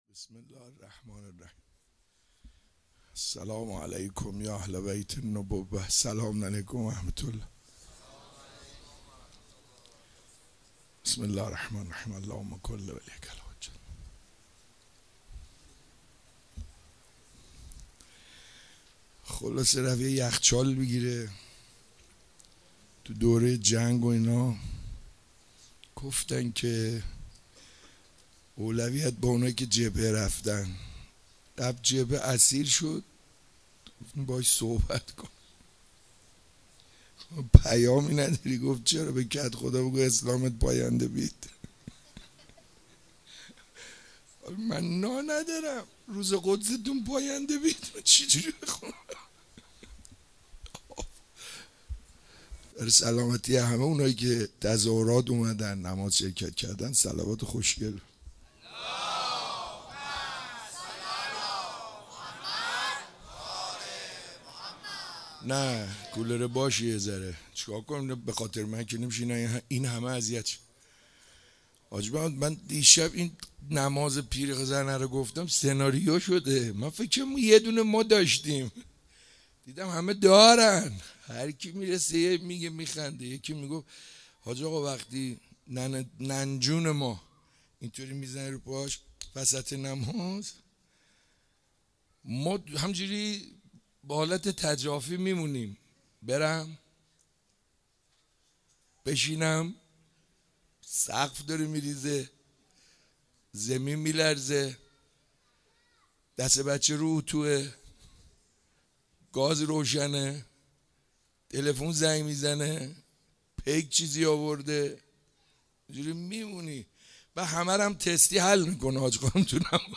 شب 29 ماه مبارک رمضان 96 - صحبت